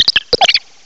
cry_not_pikipek.aif